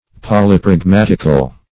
polypragmatical.mp3